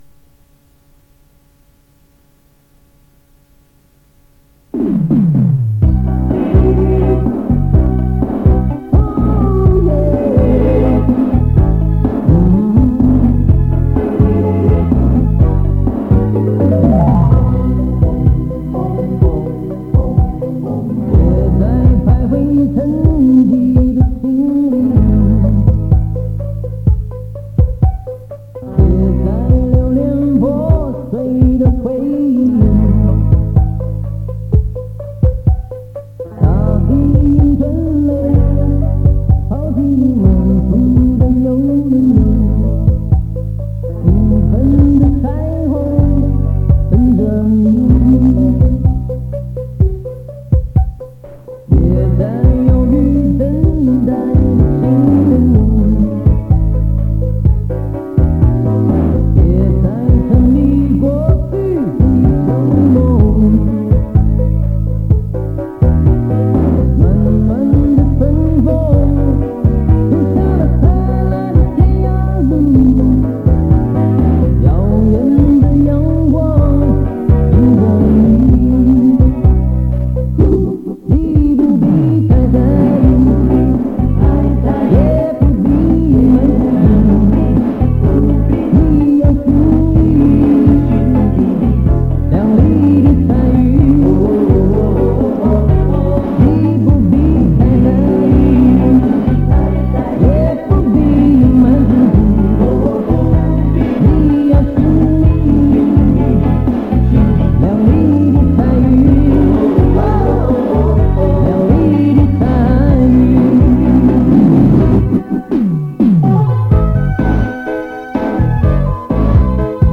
磁带数字化：2022-09-03